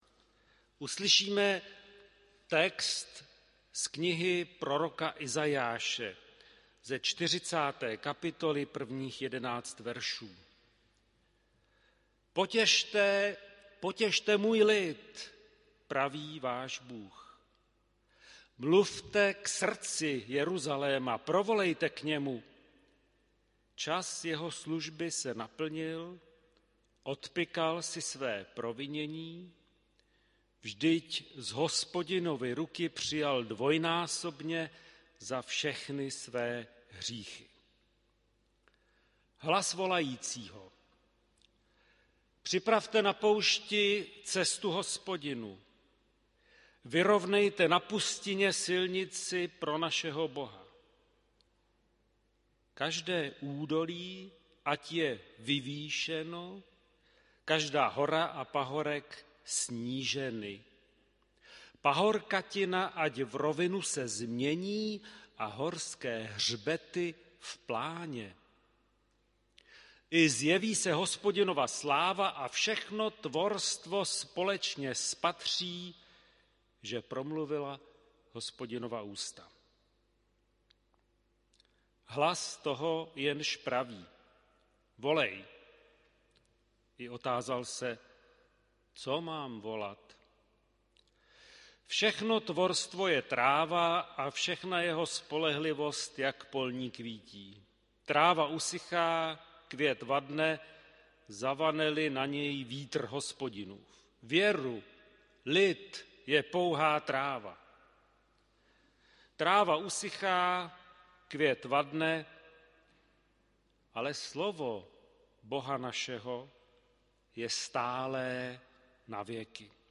Bohoslužby se slavením svaté večeře Páně.
Kázání synodního seniora bratra Pavla Pokorného na text z Izaiáše 40: 1-11 zdesborová ohlášení zde